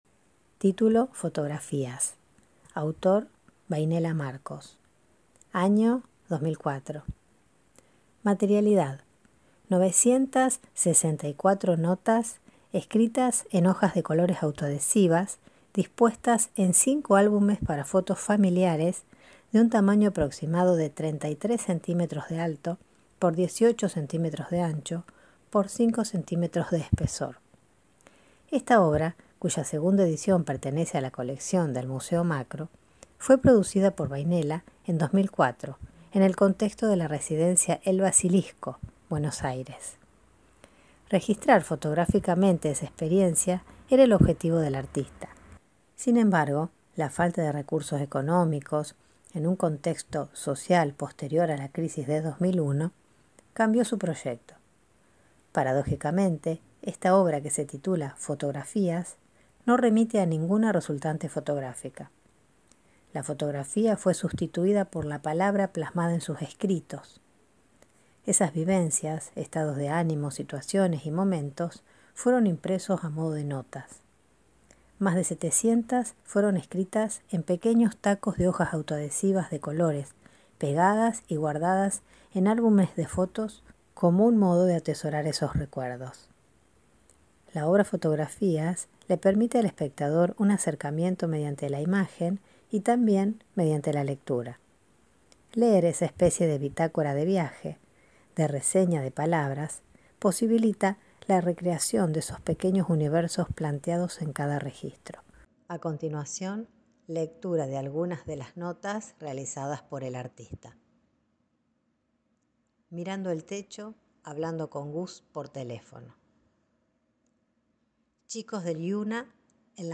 Audiodescripción de la obra Fotografías